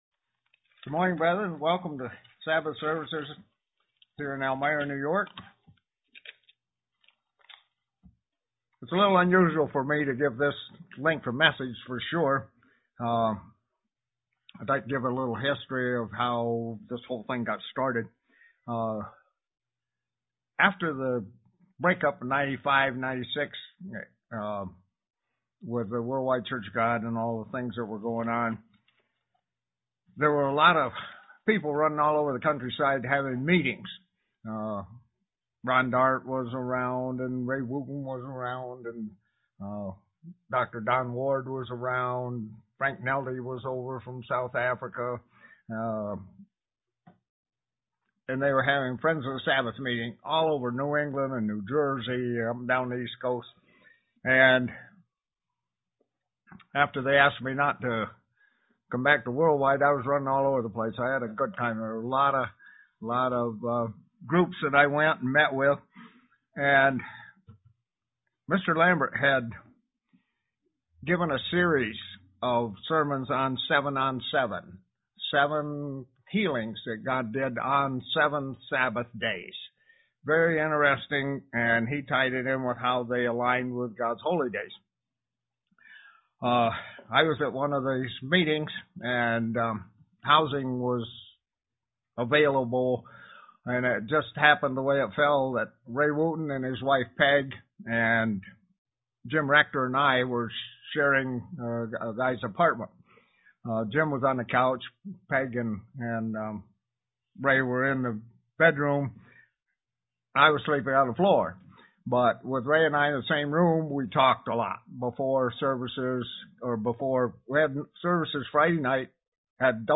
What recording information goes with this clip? Given in Elmira, NY